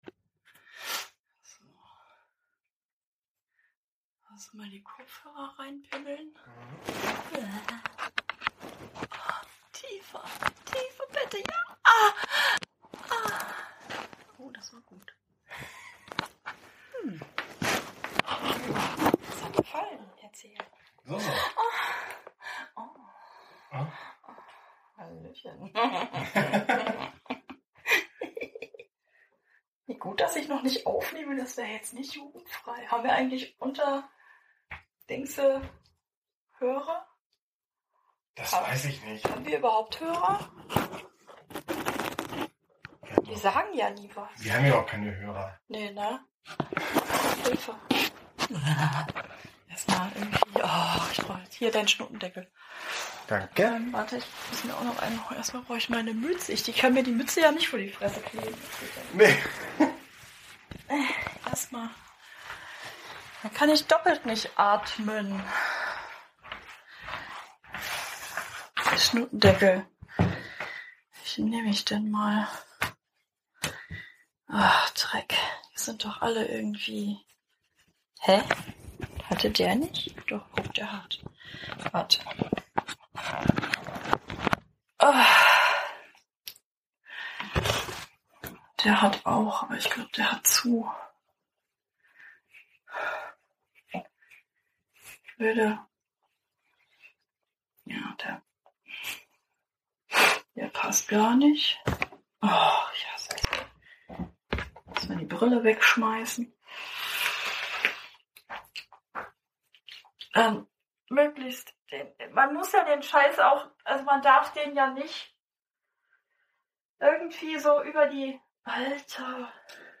Beschreibung vor 5 Jahren Ein Spaziergang mit Schnutendeckel, Behelfsmaske, Mund-Nasen-Bedeckung oder wie man es heute noch so schimpft, also durch Stoff gefilterte frische Luft. Oder von mir aus auch ein der Situation angepasster Pop-Schutz fürs Mikro.